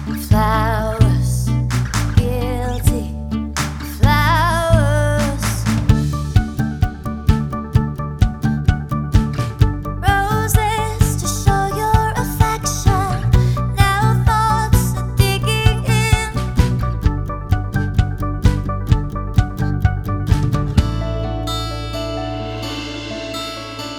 for duet Country (Female) 3:08 Buy £1.50